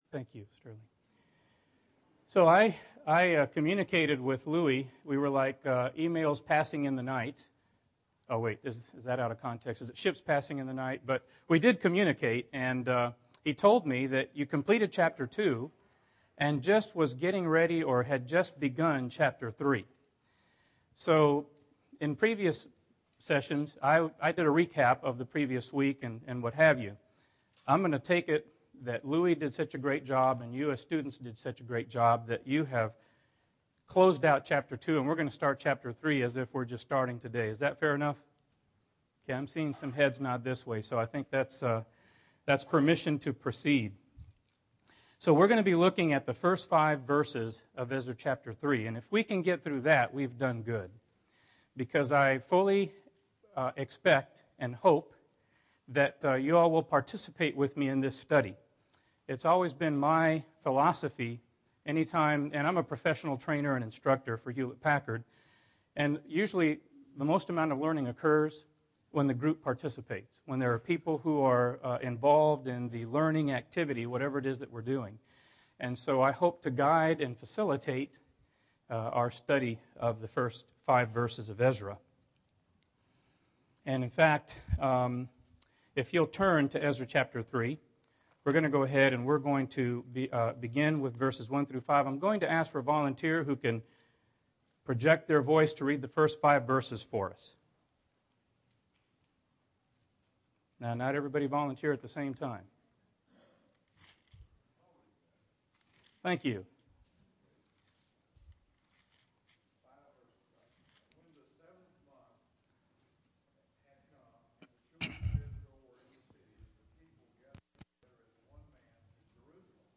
Ezra 3 (6 of 13) – Bible Lesson Recording
Sunday AM Bible Class